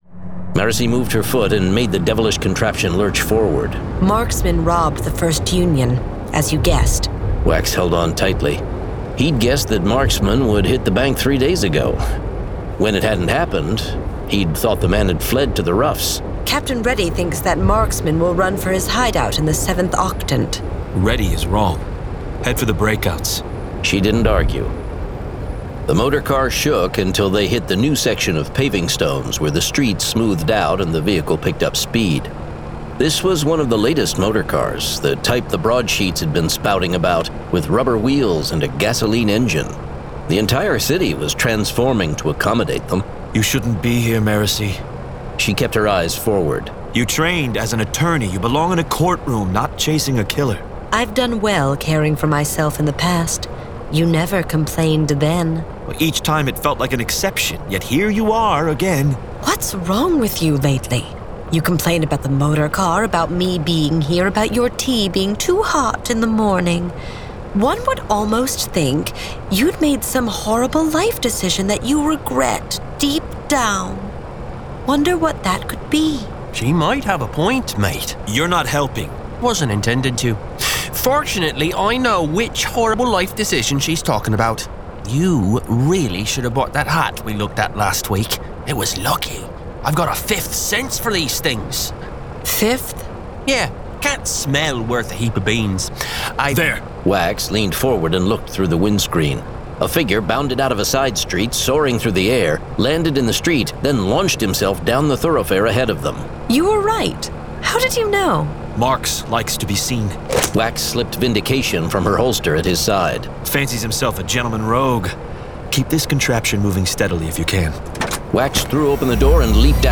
Mistborn 5: Shadows of Self [Dramatized Adaptation]